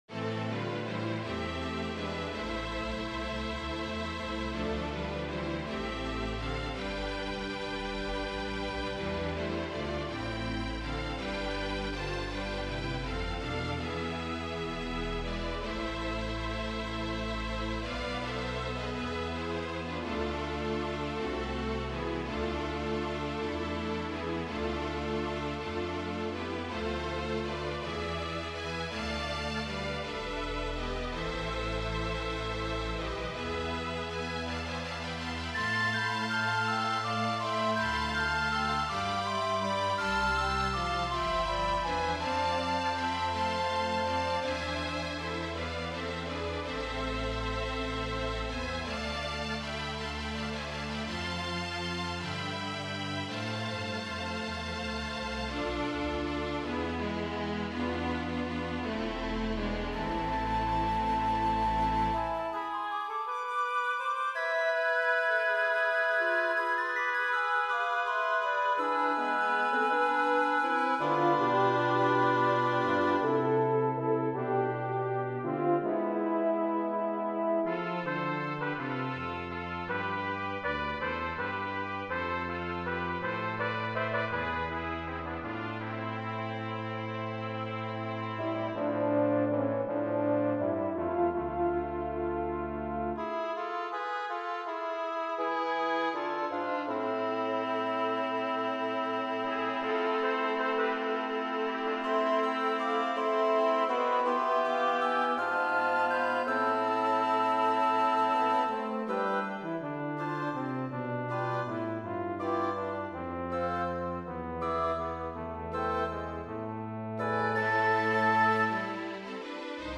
Roy Howard has composed a new work for orchestra that matches the theme of a 2008 GPAC gallery exhibit.
Flute, Soprano Recorder, Alto Recorder, Bb Clarinet, Oboe, Bassoon
Trumpets 1,2; French Horn, Trombone, Tuba
Violins 1,2; Viola, Cello, Bass